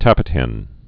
(tăpĭt-hĕn)